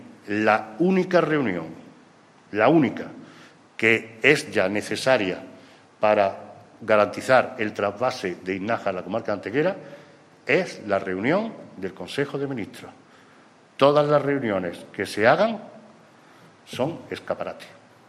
El Alcalde de Antequera ha realizado en la mañana de hoy, a petición de los medios de comunicación, una valoración en rueda de prensa sobre la situación actual del trasvase de Iznájar una vez que la Junta de Andalucía comunicaba, en el día de ayer, que la Consejería de Agricultura, Ganadería, Pesca y Desarrollo Sostenible ultima ya los trabajos para licitar un proyecto que garantizará el abastecimiento de agua en la Comarca de Antequera.